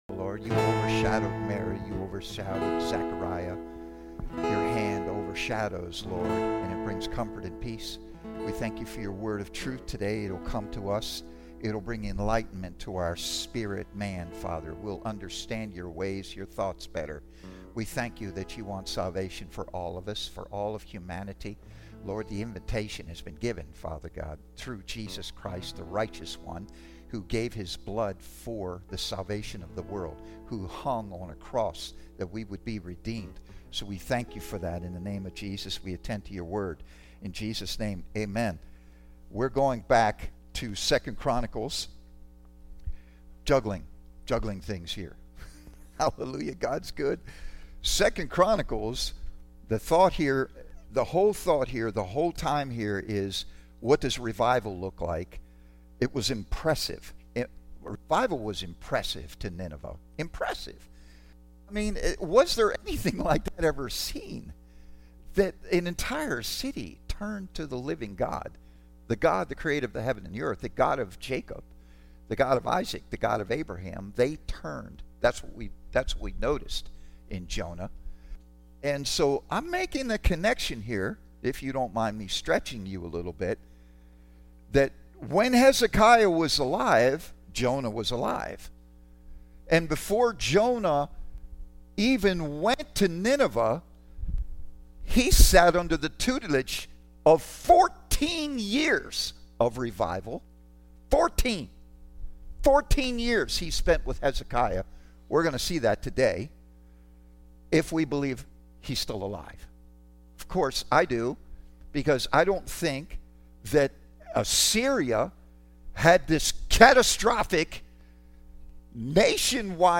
Teaching Service